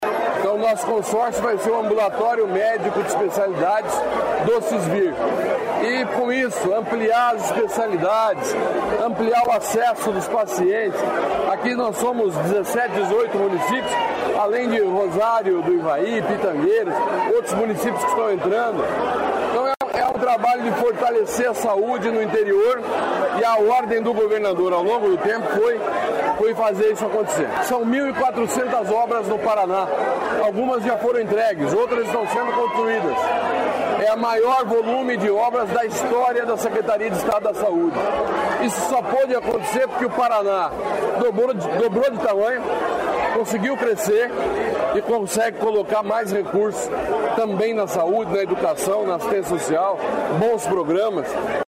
Sonora do secretário de Estado da Saúde, Beto Preto, sobre ampliação do Ambulatório de Especialidades em Apucarana